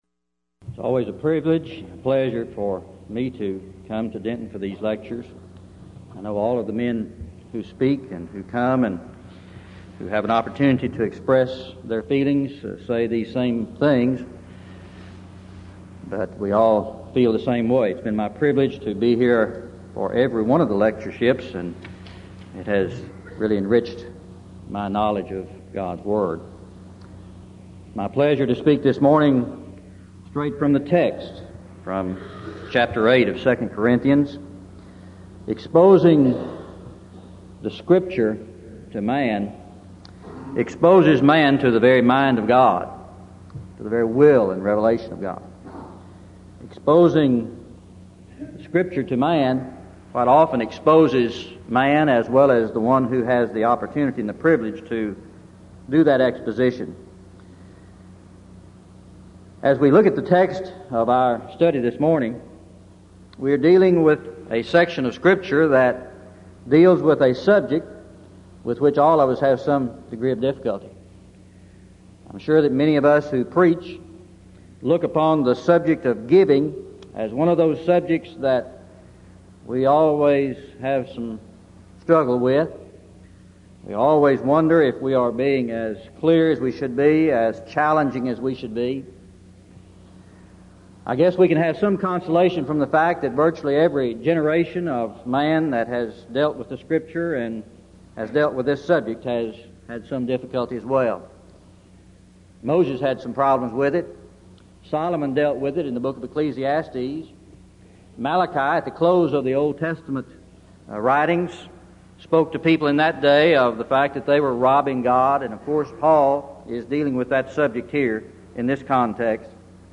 Event: 1989 Denton Lectures Theme/Title: Studies In The Book Of II Corinthians